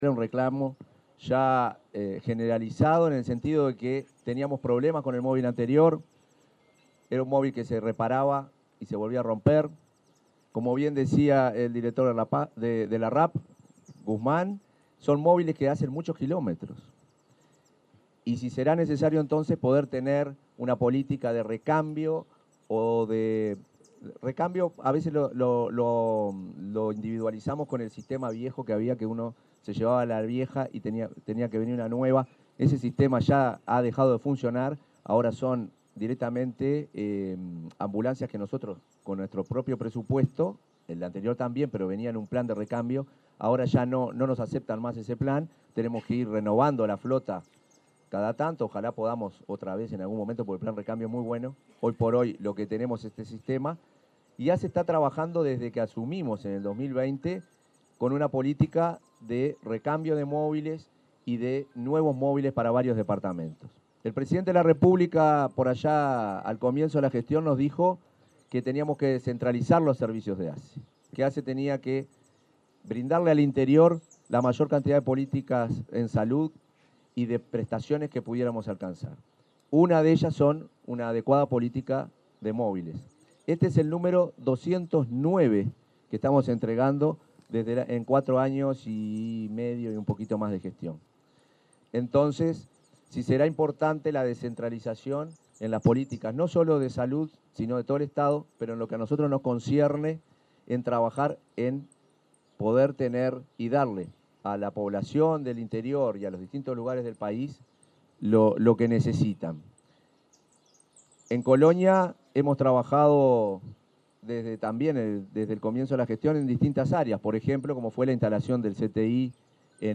Palabras del presidente de ASSE, Marcelo Sosa
En el evento, el presidente de ASSE, Marcelo Sosa, realizó declaraciones.